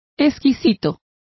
Complete with pronunciation of the translation of delicate.